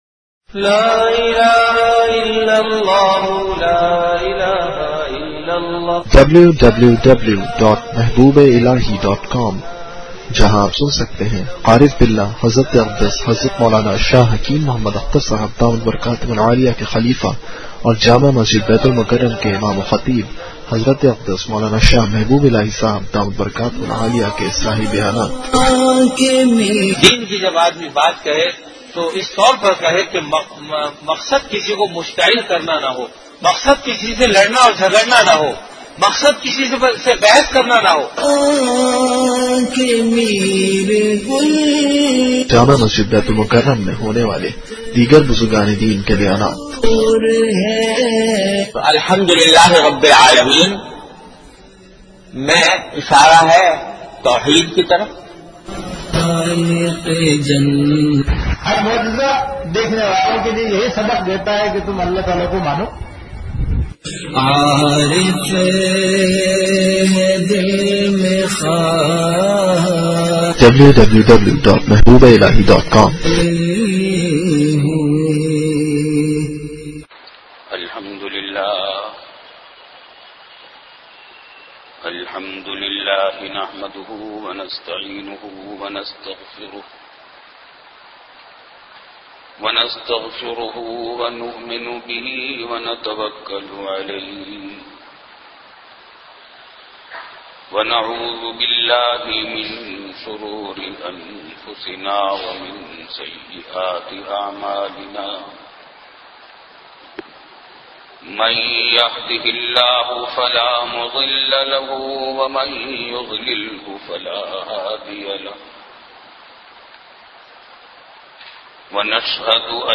An Islamic audio bayan by Hazrat Mufti Muhammad Rafi Usmani Sahab (Db) on Bayanat. Delivered at Jamia Masjid Bait-ul-Mukkaram, Karachi.